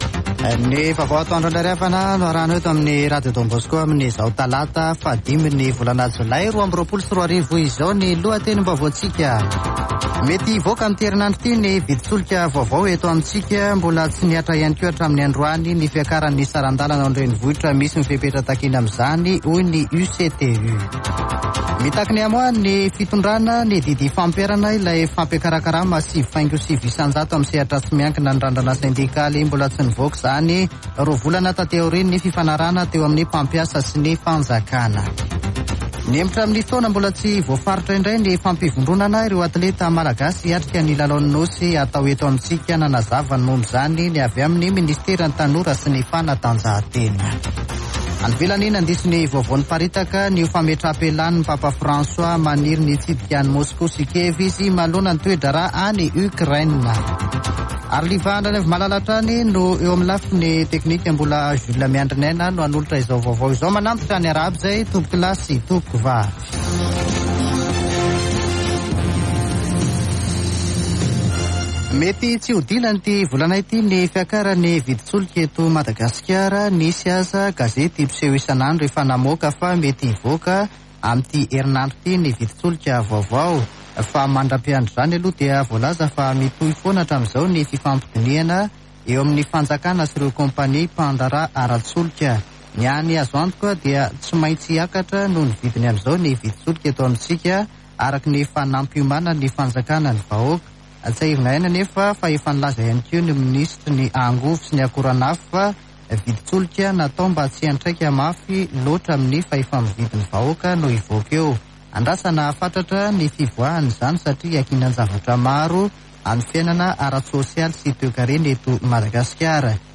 [Vaovao antoandro] Talata 05 jolay 2022